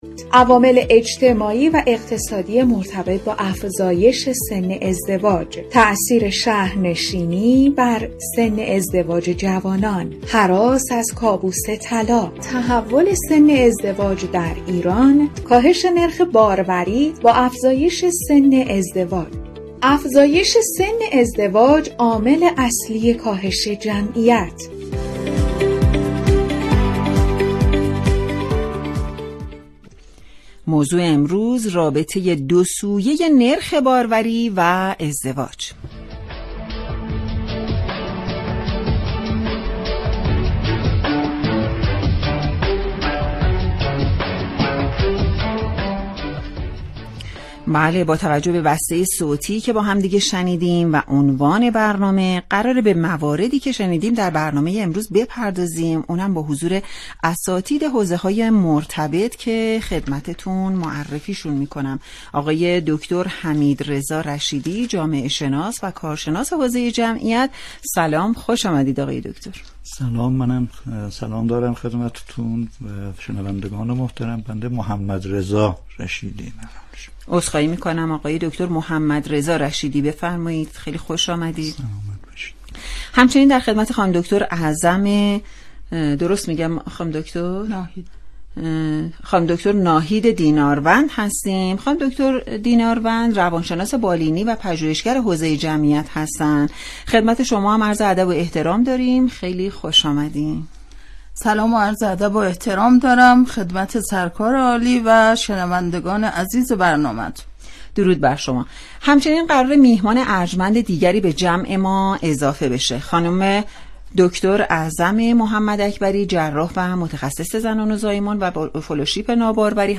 این برنامه روز چهارشنبه، 6 دی ماه 1402 ساعت 11:00 بصورت زنده پخش گردید.